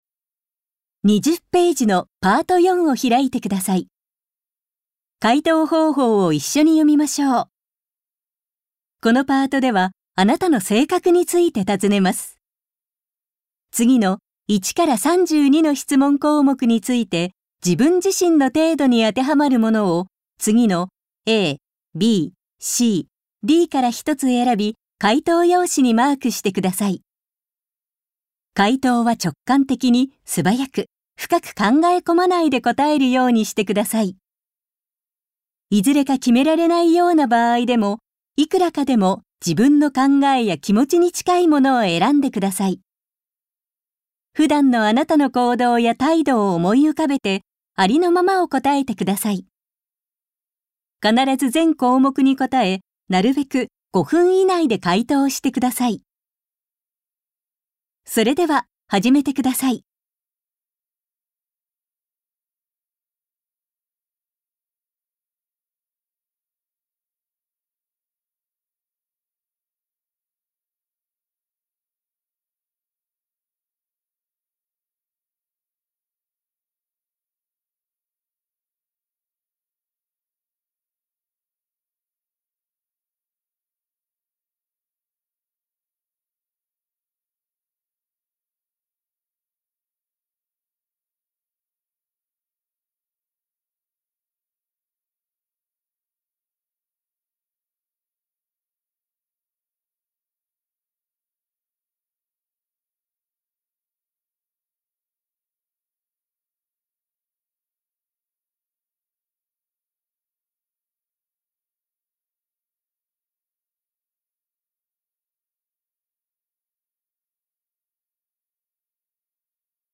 実施用音声ガイドは、検査実施時の監督者の指示をすべて収録しています。